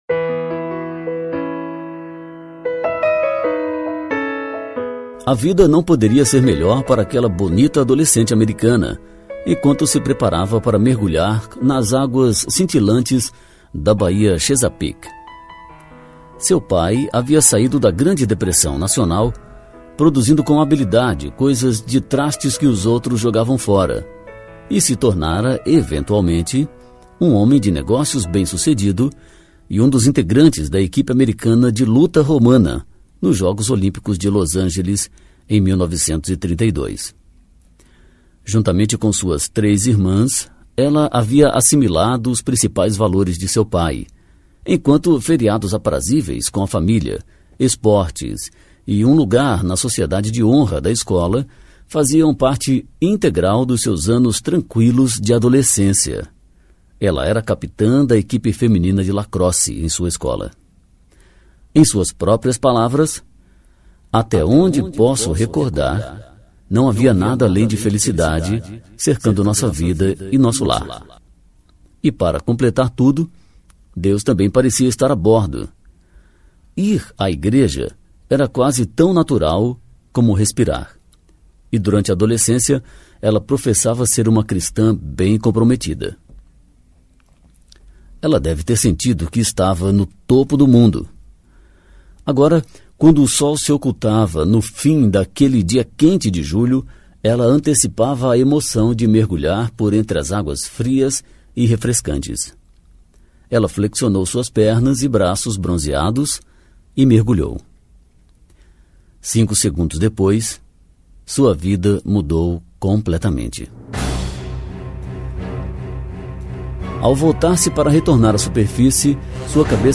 John Blanchard 30,757 Visualizações Audiolivro